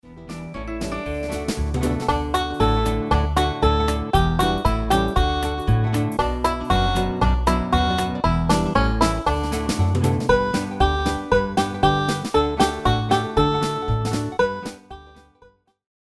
RÉPERTOIRE  ENFANTS
Le PLAY-BACK mp3 est la version
instrumentale complète, non chantée,